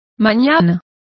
Complete with pronunciation of the translation of tomorrows.